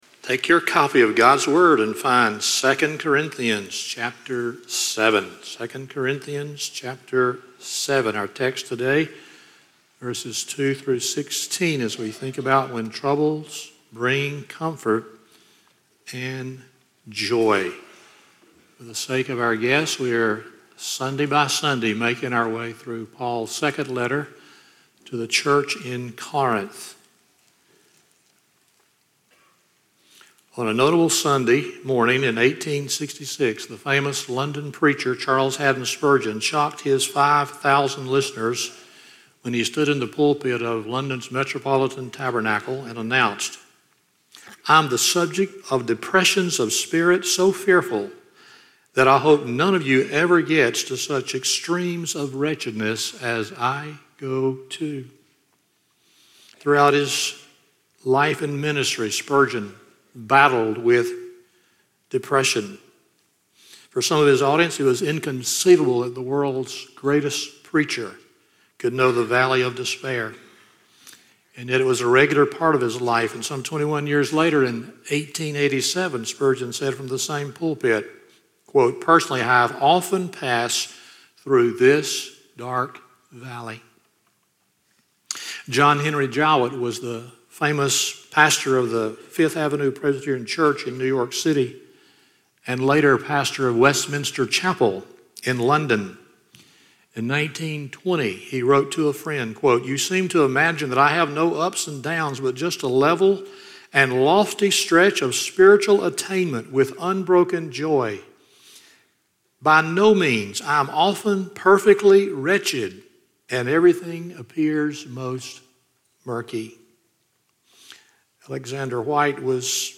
2 Corinthians 7:2-16 Service Type: Sunday Morning 1.